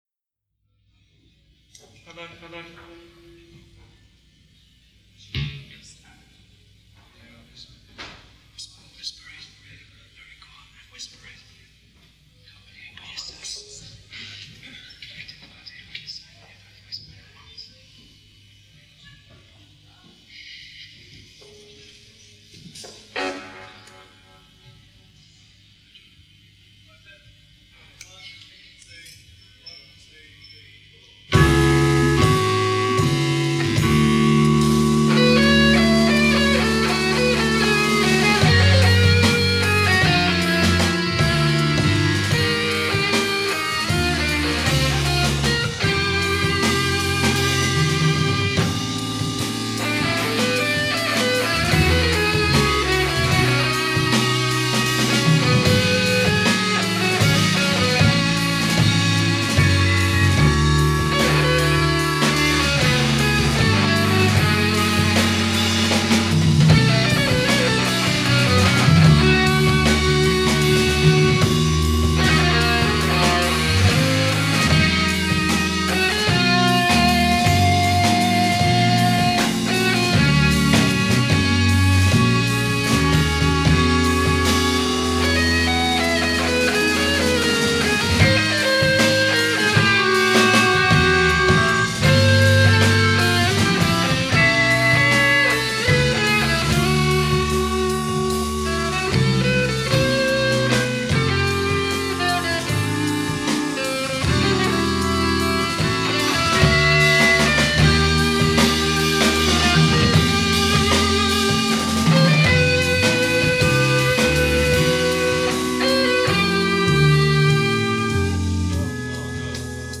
In Concert from Holland
Pivotal Progrock Edition.